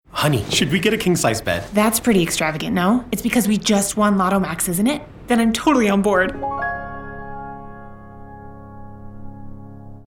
Publicité (Loto-Québec) - ANG